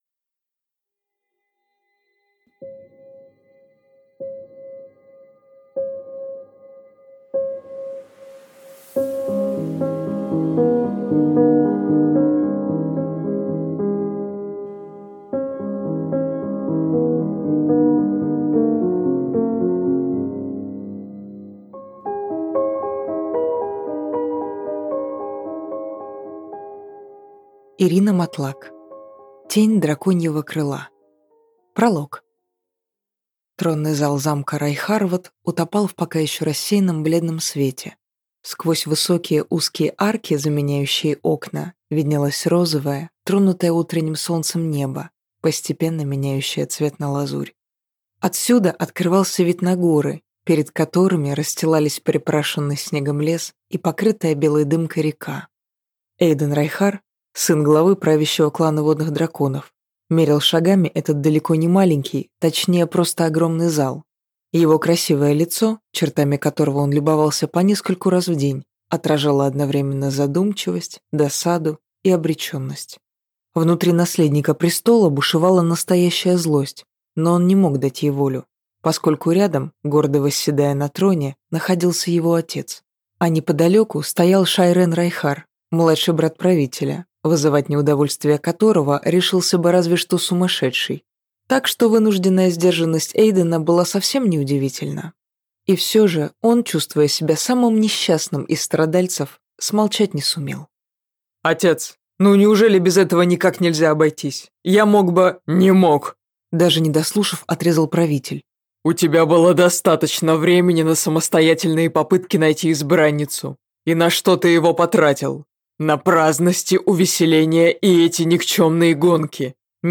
Аудиокнига Тень драконьего крыла | Библиотека аудиокниг